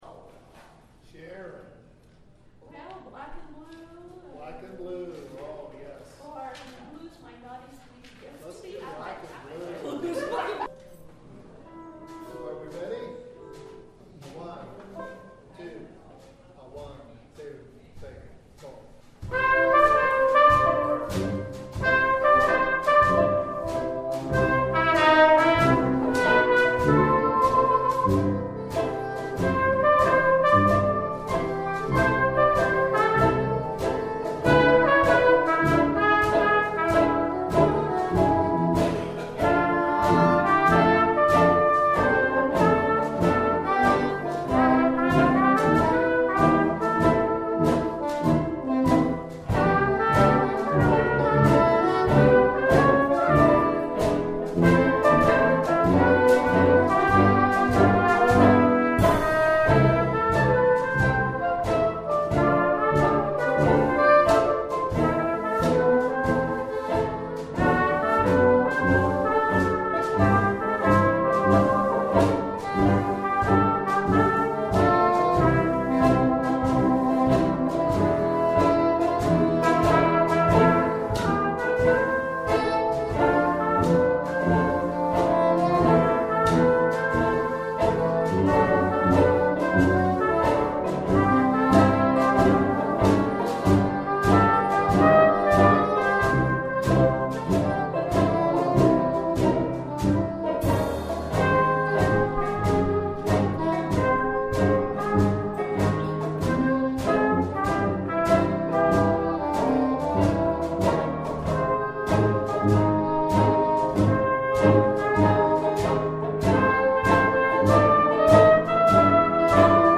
Piano
Banjo
Trombone
SETTING: Jam sessions and practice sessions. There are no studio recordings in this list.
We added as much improv as appropriate.